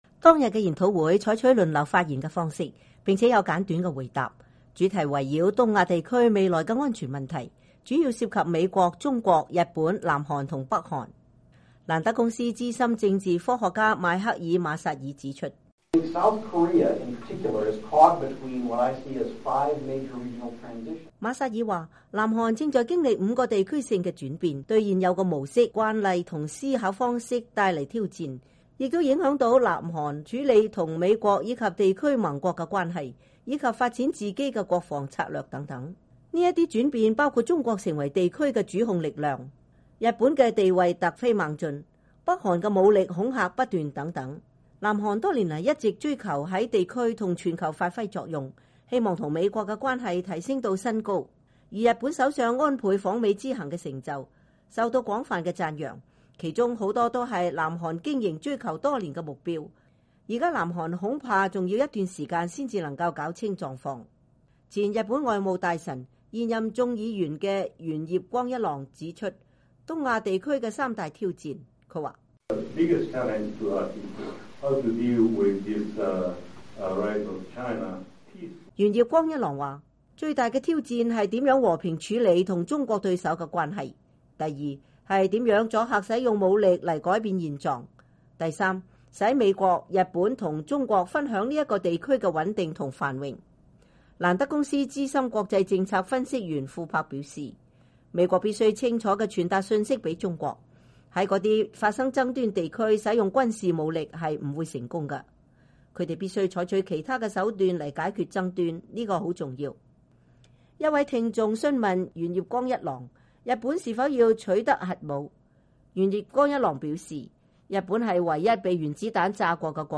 研討會歷時四個多小時結束，有一百多人出席。